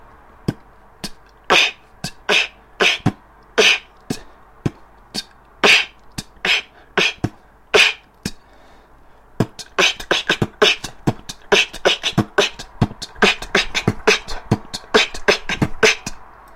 Выкладываем видео / аудио с битбоксом
Новый бит - опять же ремикс драмандбаса), в принципе похожий на брейк бит:
b t kch t kch kch b kch t
kch_kchkch.mp3